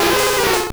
Cri de Lokhlass dans Pokémon Or et Argent.